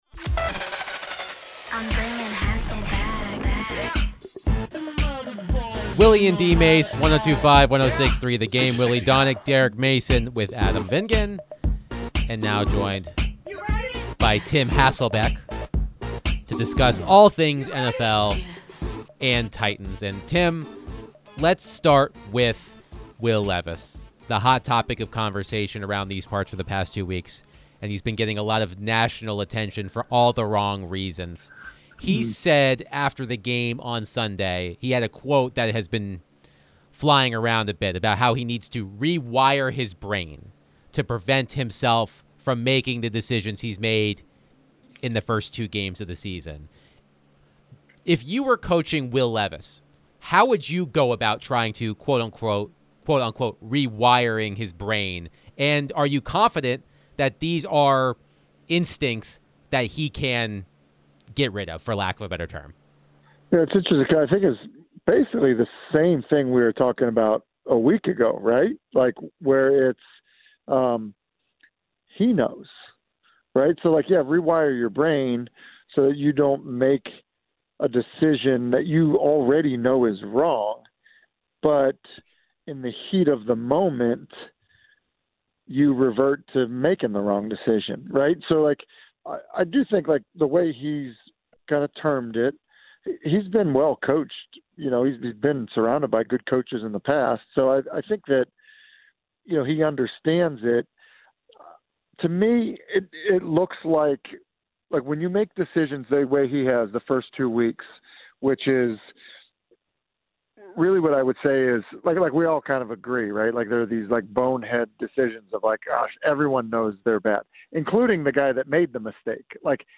ESPN NFL Analyst Tim Hasselbeck joined the show to discuss the Titans' starting quarterback situation. Do the Titans need to move on from Will Levis?